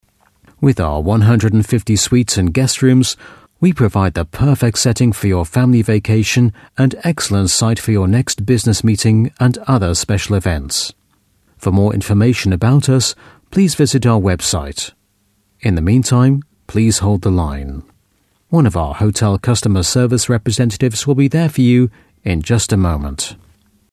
Native Speaker
Englisch (UK)
Telefonansagen
Hotel greeting